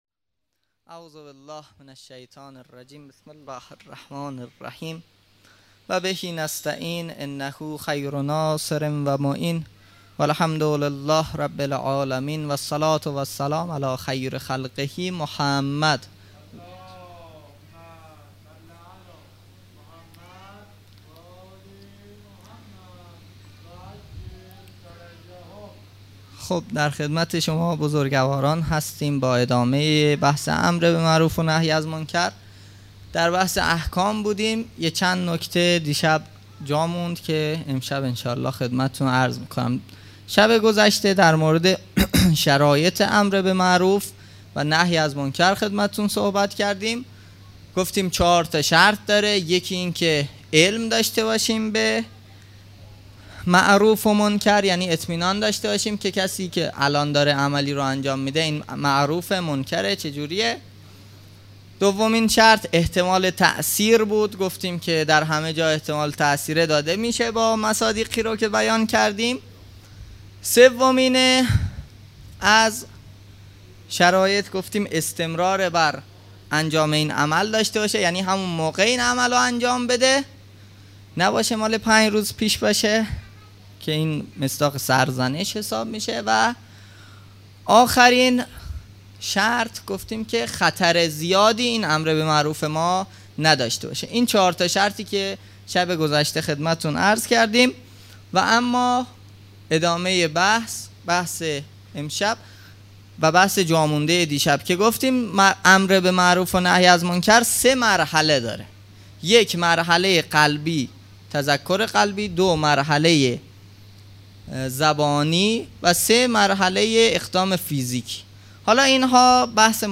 مراسم عزاداری محرم ۱۴۴۳_شب پنجم